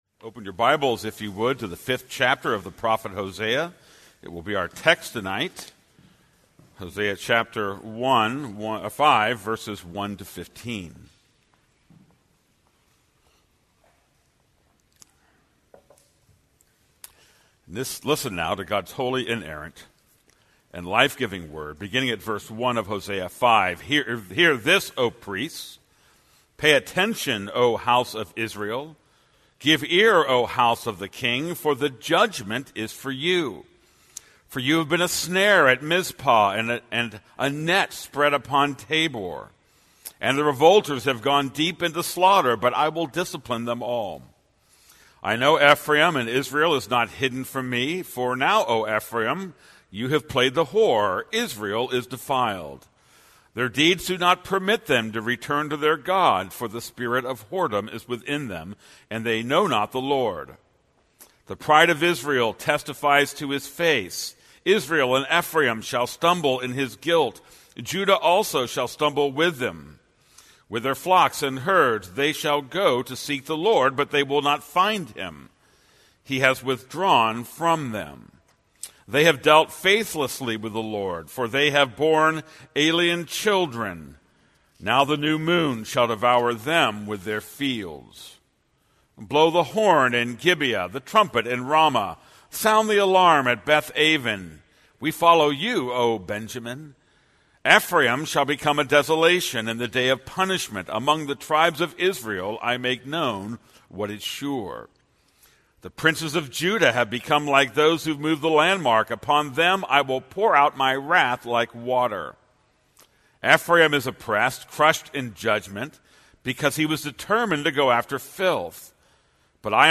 This is a sermon on Hosea 5:1-15.